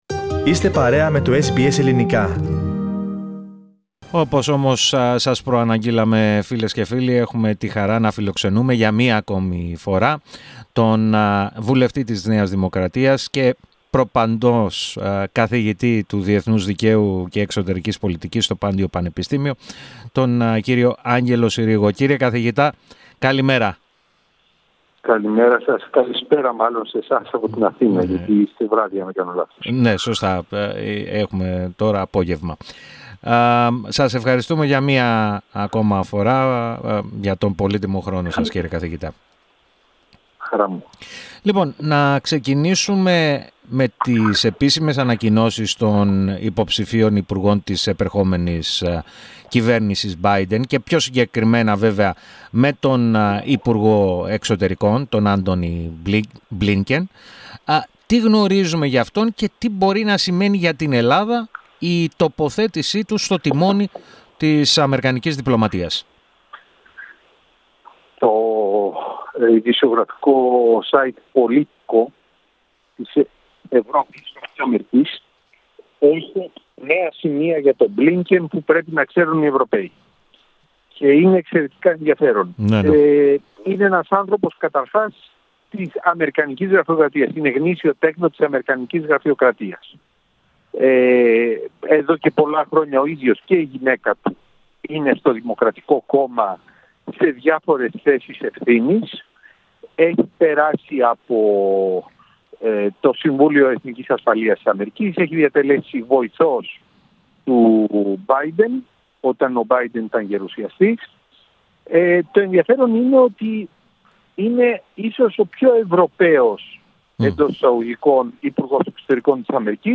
Ο βουλευτής της ΝΔ, και αναπληρωτής καθηγητής του Διεθνούς Δικαίου και της Εξωτερικής Πολιτικής στο Πάντειο Πανεπιστήμιο, μίλησε στο Ελληνικό Πρόγραμμα της ραδιοφωνίας, SBS, στον απόηχο των επίσημων ανακοινώσεων για τους υποψήφιους υπουργούς της επερχόμενης κυβέρνησης Μπάιντεν.